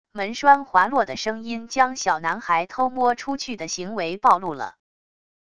门栓滑落的声音将小男孩偷摸出去的行为暴露了wav音频